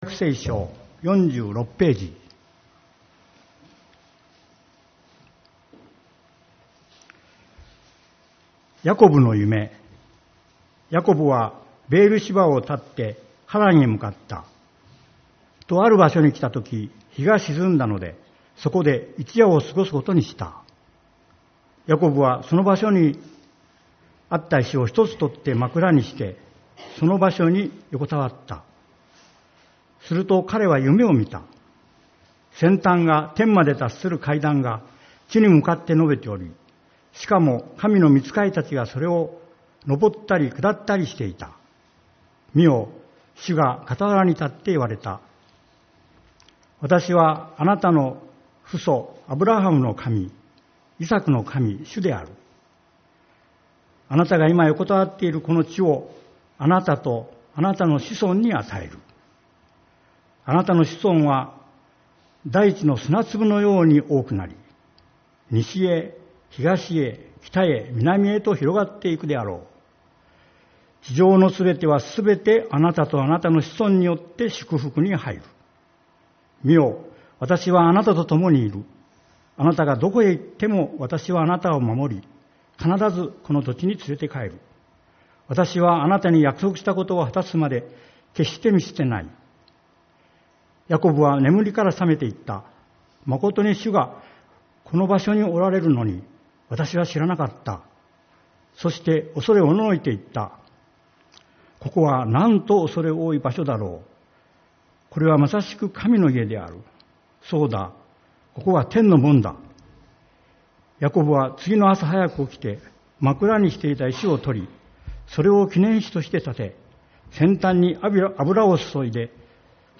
8月25日主日礼拝